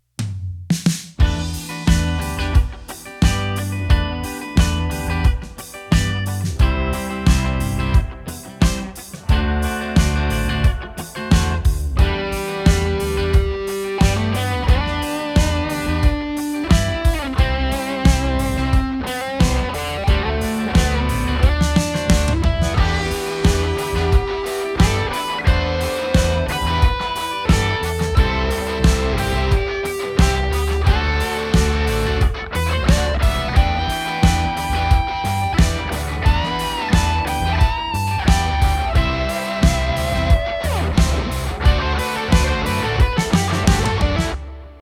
Neural DSP models John Mayer’s entire amp and effects rig—and it sounds great
Mayer-demo.m4a